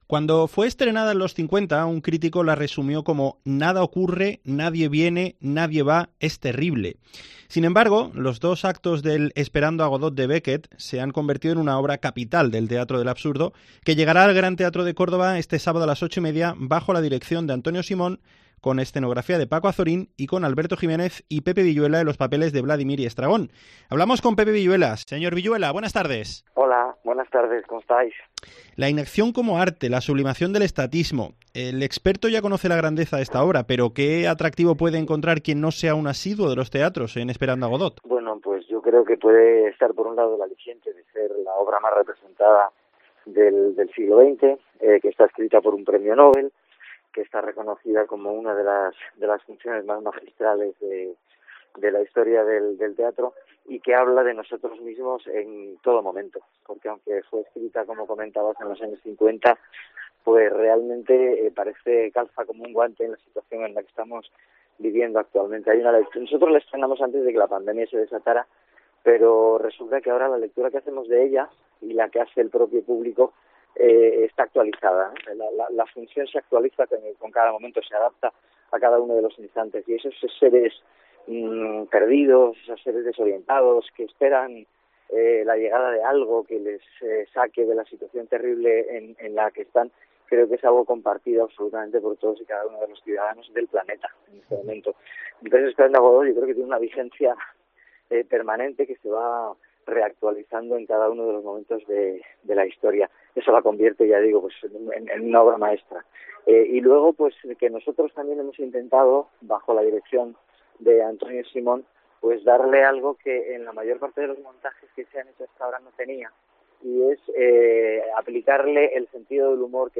Entrevista Pepe Viyuela